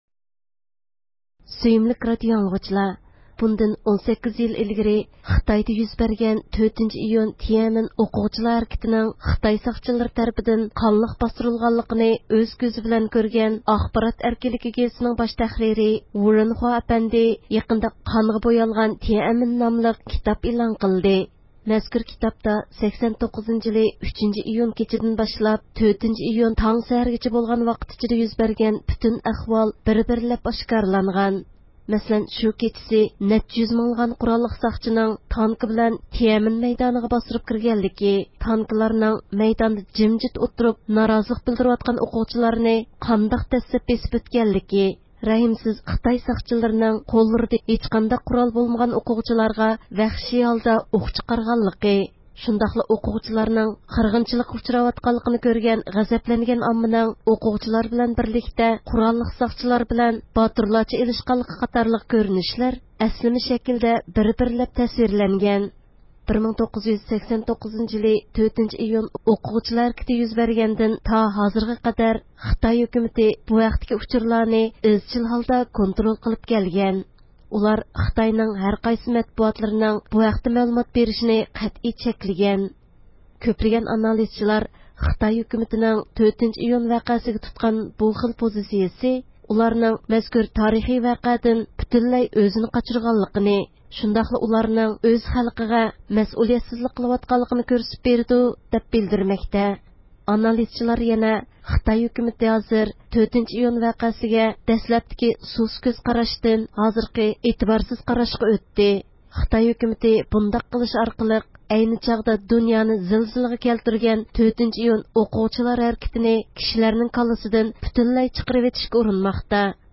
رادىئومىزنىڭ زىيارىتىنى قوبۇل قىلدى